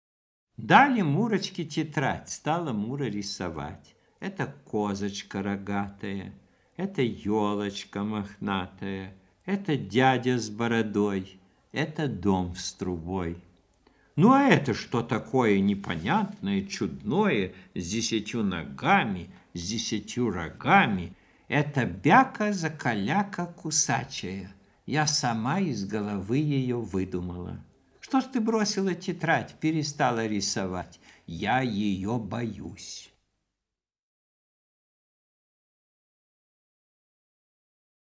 K.sobstv.prochtenie-Zakalyaka-stih-club-ru.mp3